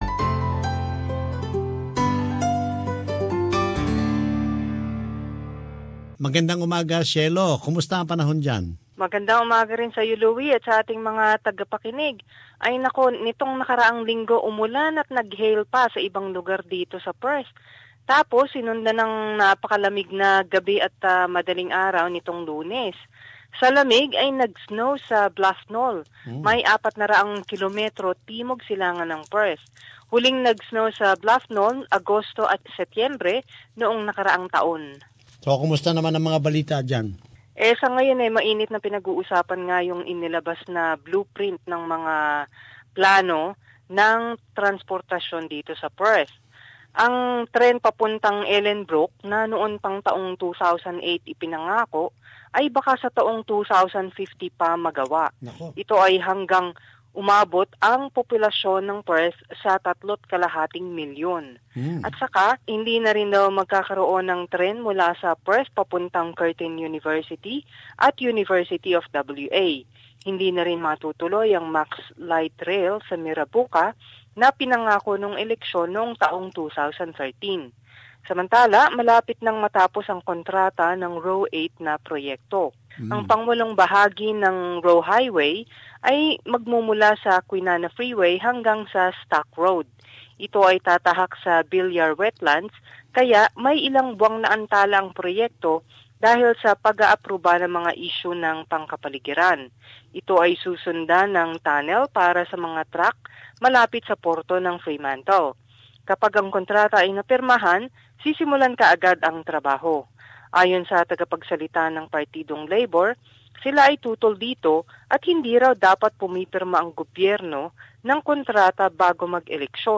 Perth report.